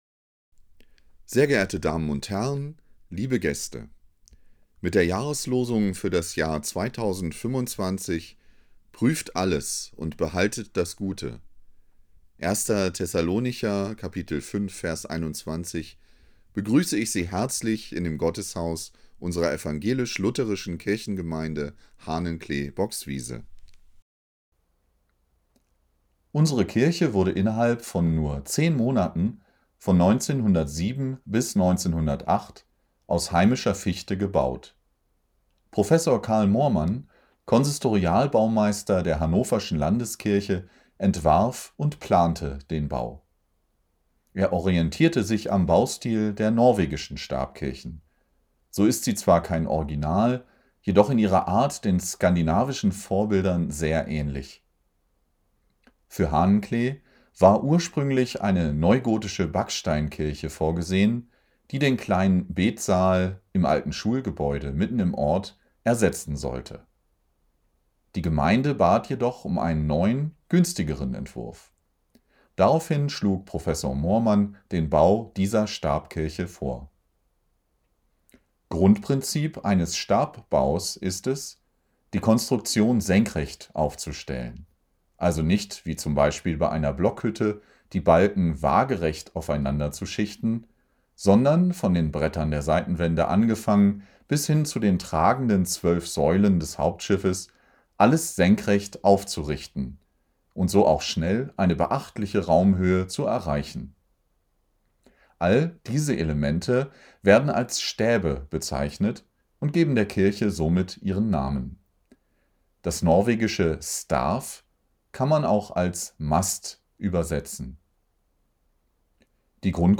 Unsere Audioführung können Sie unter folgendem Link anhören: Führung 2025 Seit langen Jahren haben wir eine herzliche Partnerschaft mit der Kirche Wang in Karpacz im Riesengebirge.